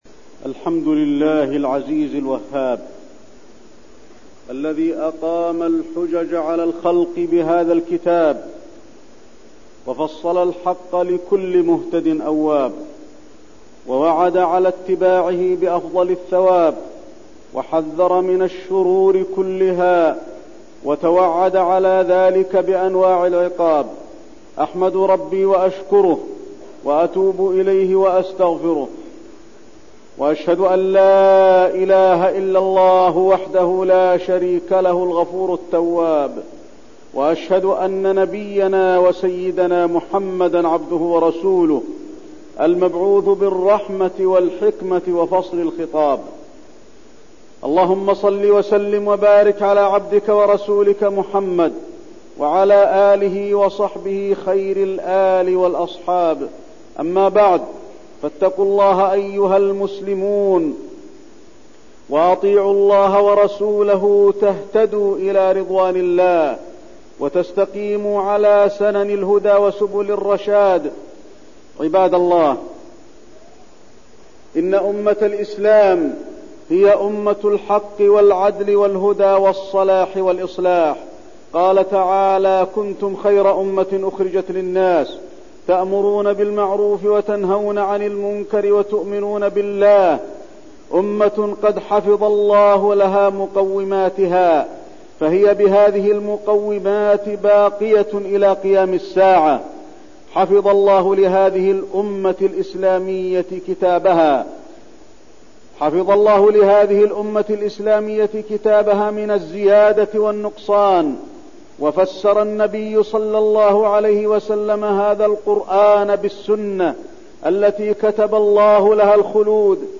تاريخ النشر ٢٧ صفر ١٤١٢ هـ المكان: المسجد النبوي الشيخ: فضيلة الشيخ د. علي بن عبدالرحمن الحذيفي فضيلة الشيخ د. علي بن عبدالرحمن الحذيفي مكانة العلماء